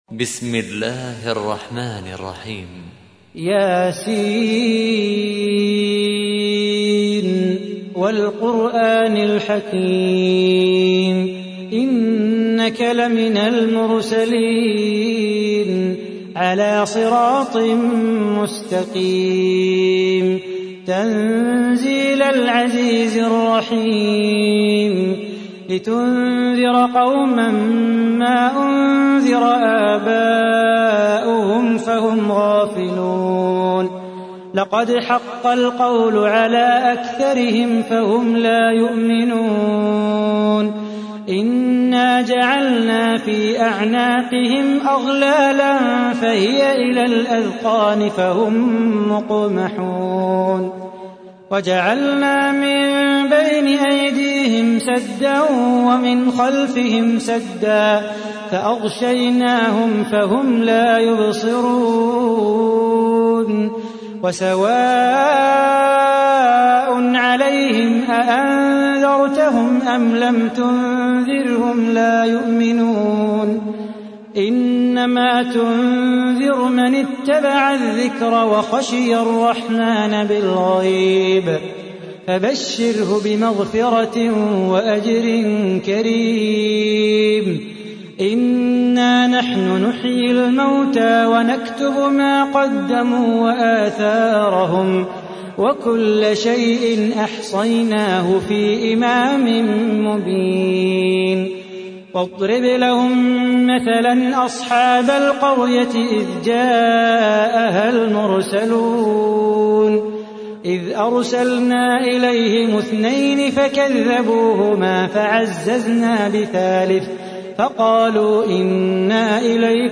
تحميل : 36. سورة يس / القارئ صلاح بو خاطر / القرآن الكريم / موقع يا حسين